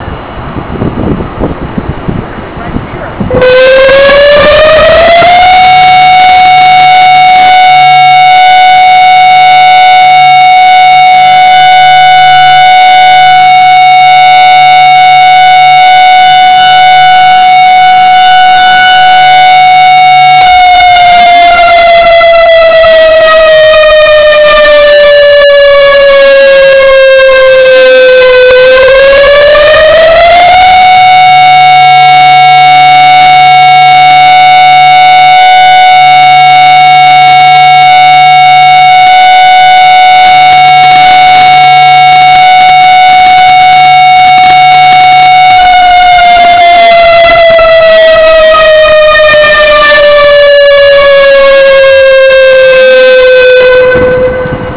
At noon on the first Wednesday of the month, you may hear the test of our ammonia siren.
• What it sounds like: You will hear a loud siren that alternates between a high and a low pitch. It is accompanied by a voice giving instructions to shelter in place or indicating that the siren is a test.
• What to do if you hear the siren testing: The siren will include a verbal announcement confirming this was a test.
B62 Siren.WAV